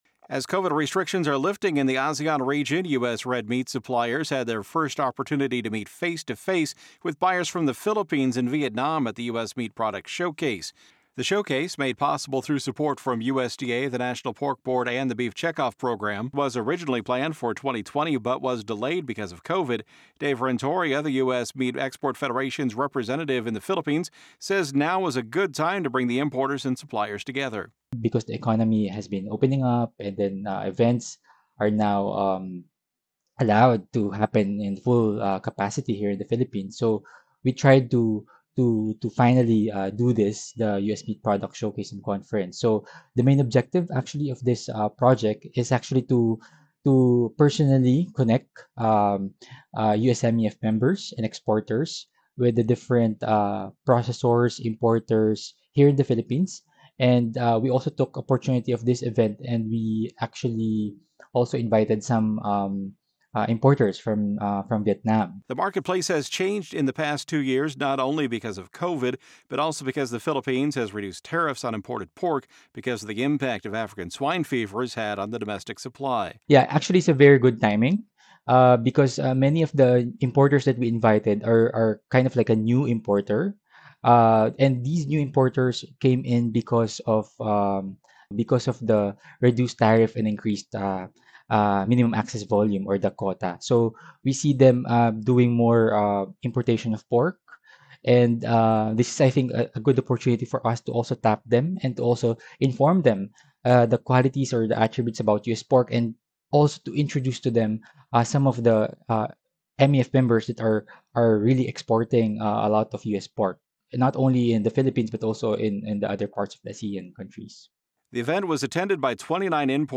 In this USMEF report